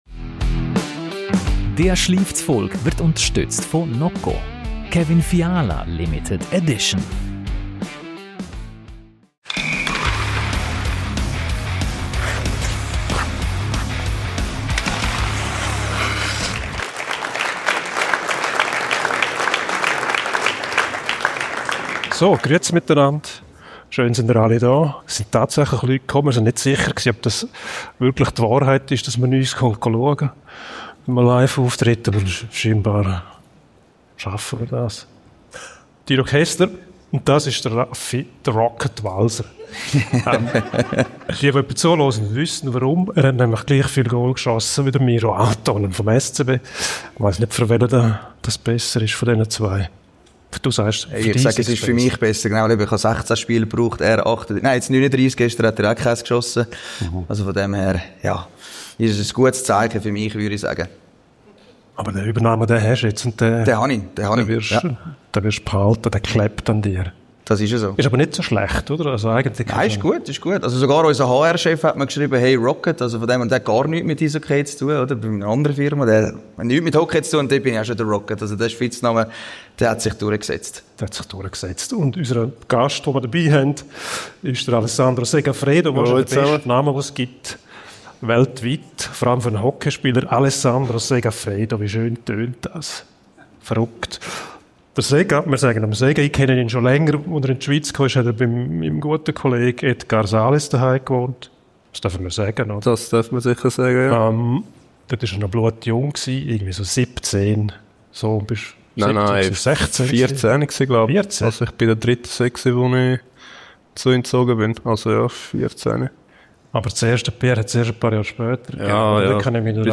- der Eishockey-Podcast der Schweiz SCHLIIFTS?